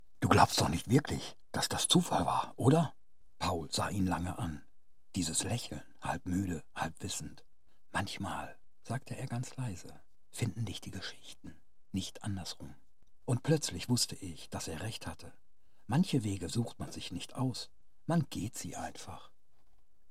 Hörspiel – Erzählerisch
Studio-quality recordings.